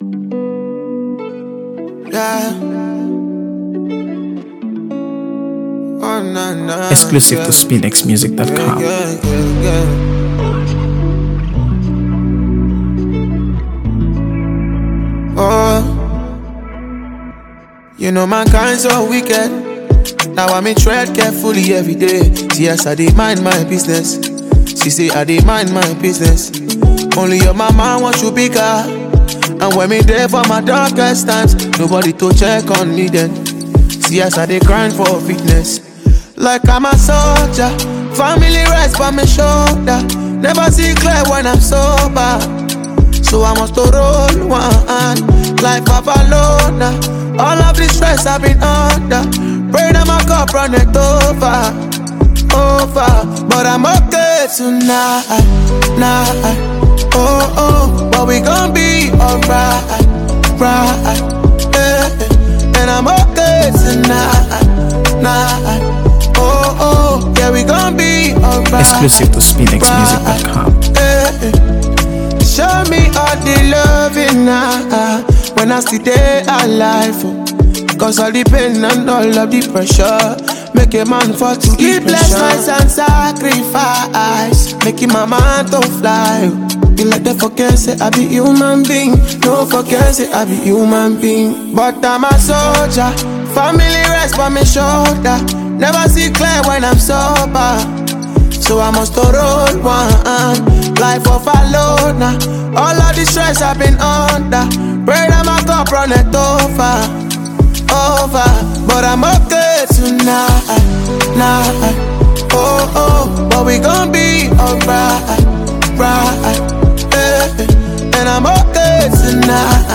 AfroBeats | AfroBeats songs
Nigerian singer
smooth, laid-back production